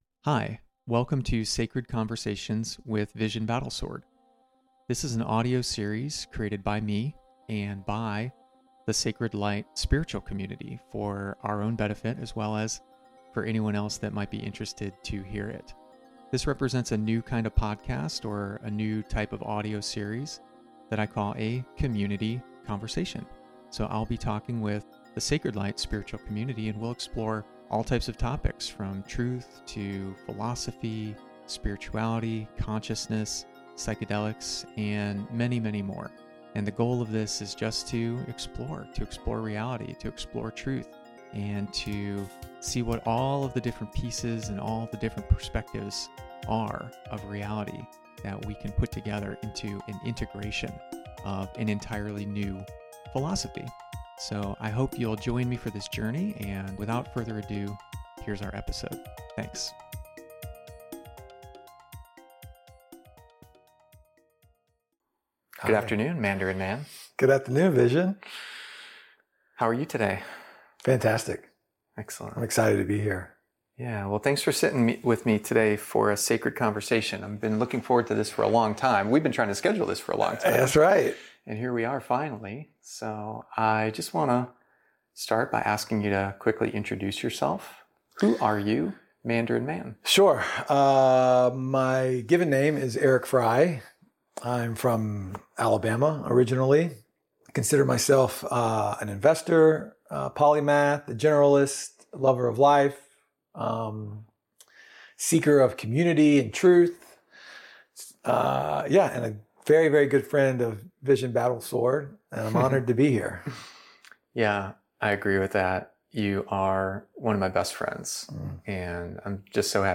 conversation05-church.mp3